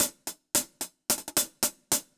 Index of /musicradar/ultimate-hihat-samples/110bpm
UHH_AcoustiHatB_110-03.wav